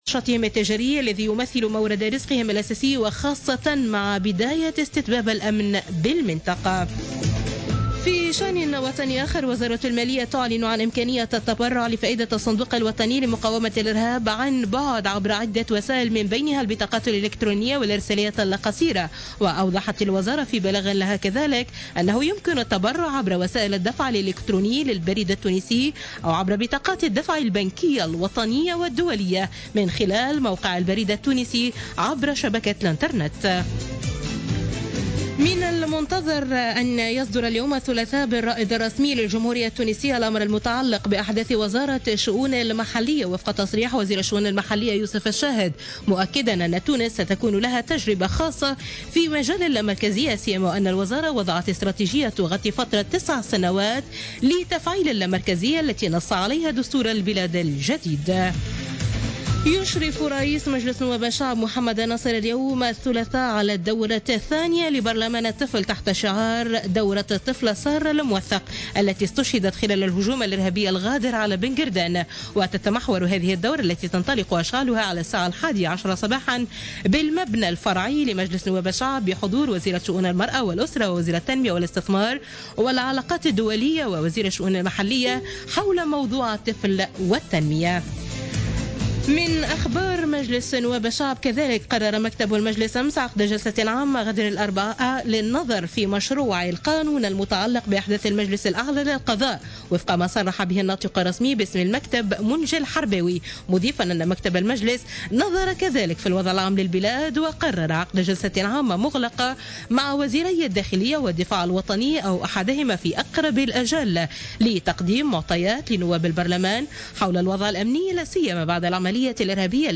Journal Info 00h00 du mardi 22 mars 2016